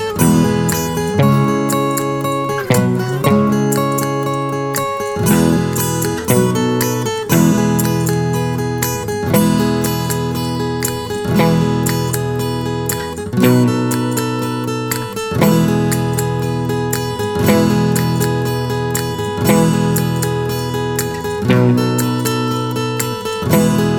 With Harmony Pop (1950s) 2:37 Buy £1.50